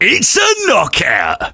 sam_kill_vo_01.ogg